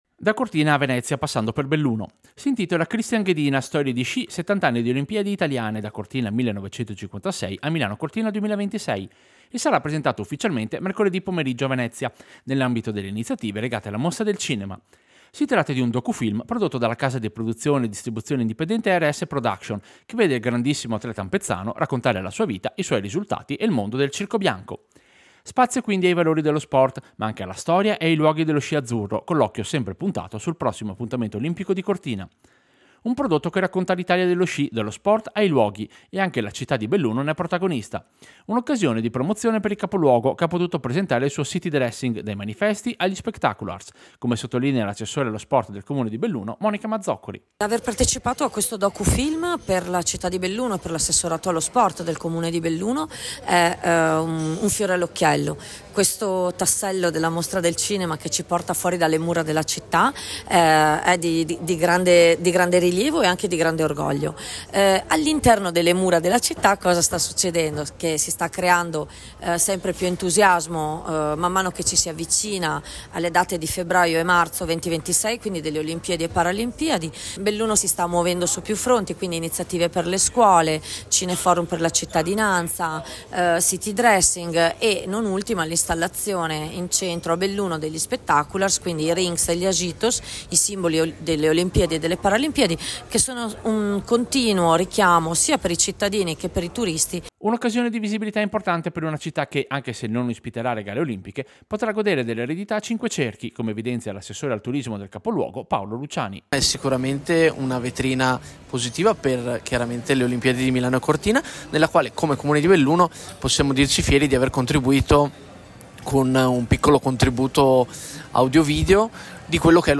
Servizio-Docufilm-Ghedina-Olimpiadi-a-Venezia.mp3